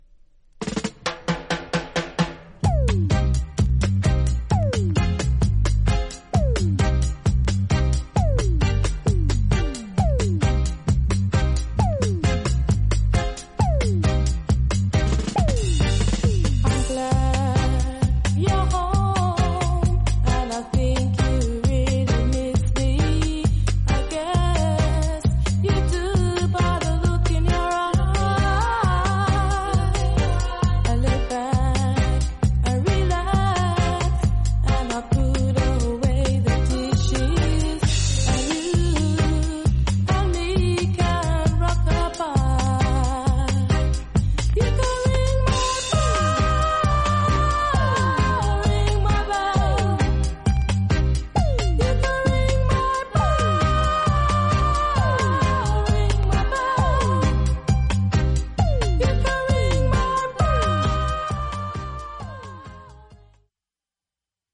実際のレコードからのサンプル↓
類別 雷鬼